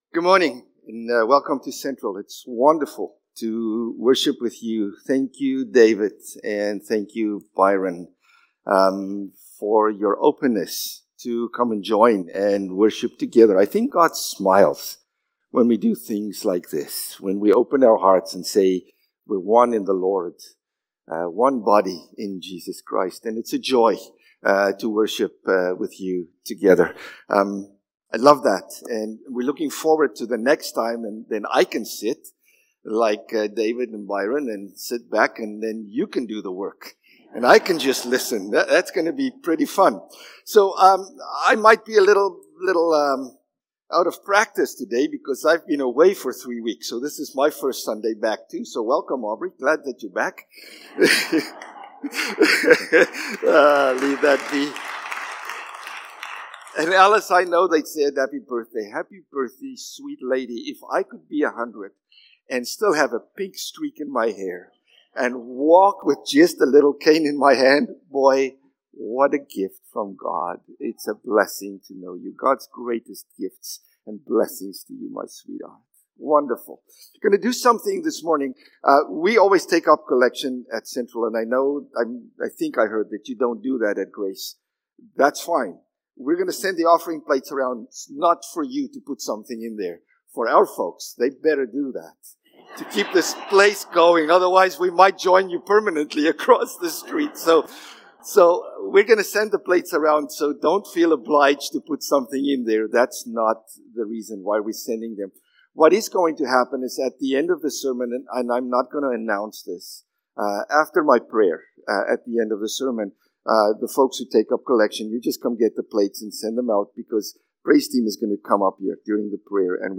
February-23-Sermon.mp3